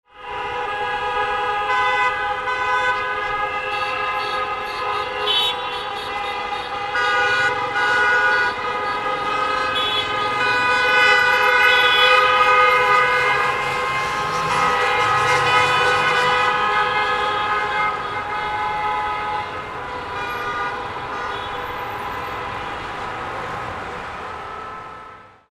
Cars Honking On The Street Sound Effect
Loud and continuous car horn honking on a busy city street – protest or celebration sound effect. Car sounds.
Cars-honking-on-the-street-sound-effect.mp3